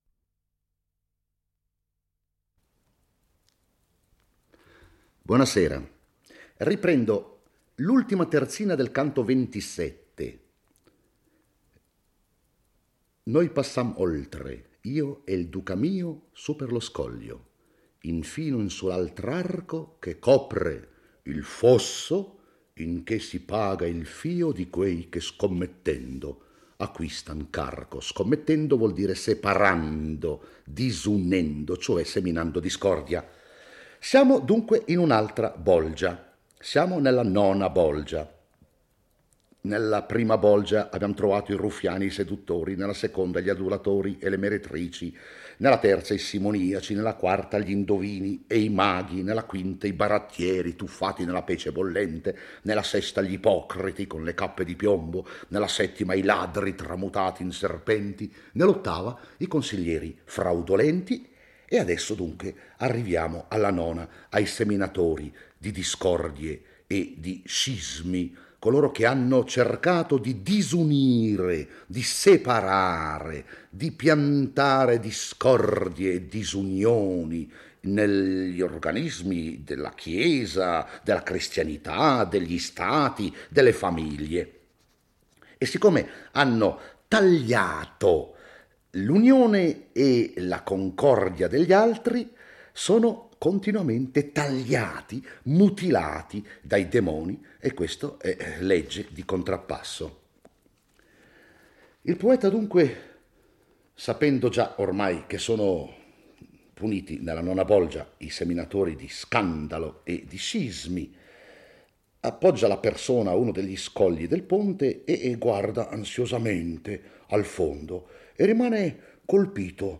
legge e commenta il XXVIII canto dell'Inferno. Dante e Virgilio giungono alla nona bolgia dell'ottavo cerchio, dedicata ai seminatori di discordie e scismi: sono coloro che nel mondo hanno diviso famiglie, comunità civili e religiose. La loro pena all'Inferno è quella di essere mutilati orribilmente dalla spada di un demonio ogni volta che gli passano di fronte nella loro eterna e lenta processione.